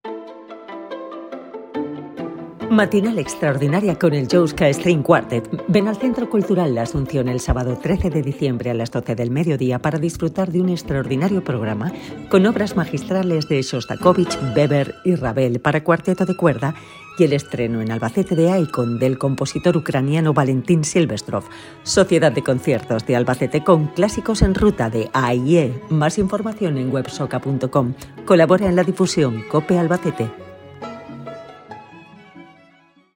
CUÑA radiofónica COPE Albacete